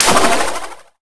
water3.wav